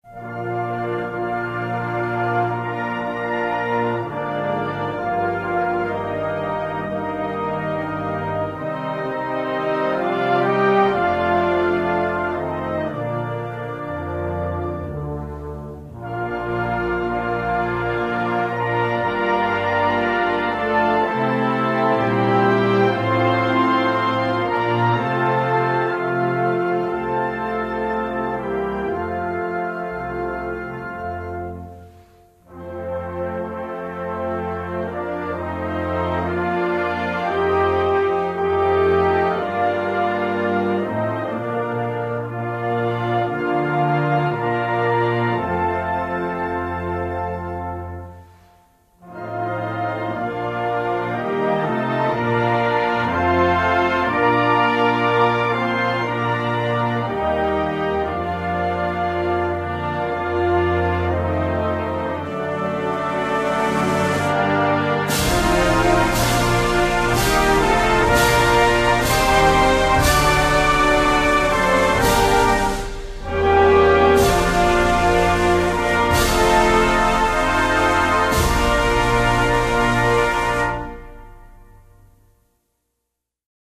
國歌